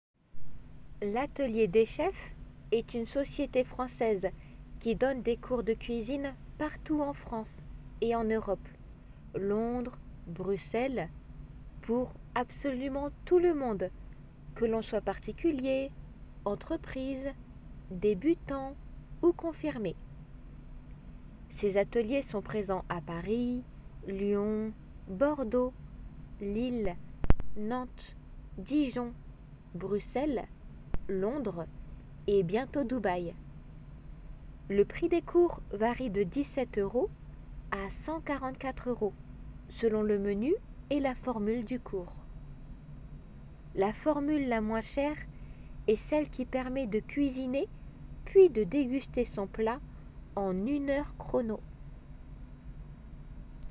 デイクテ
自然の速さで